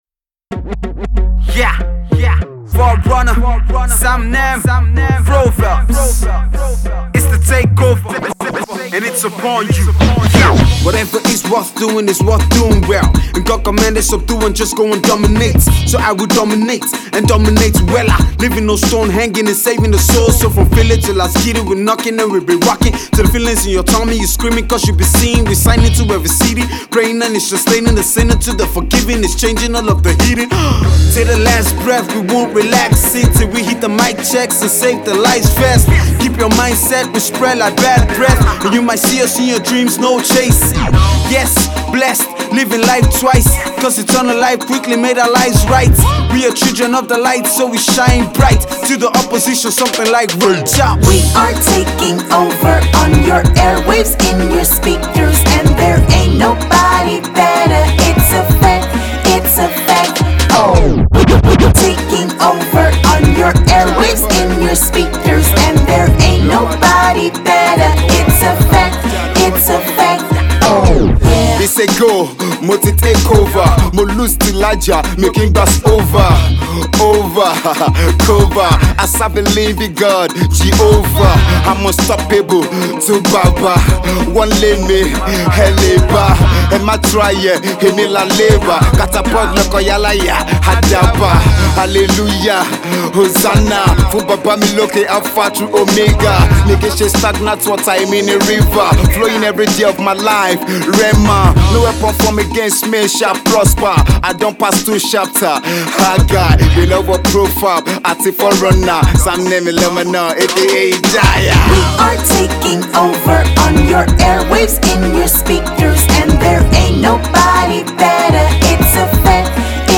hip hop jam
with a naija feel to it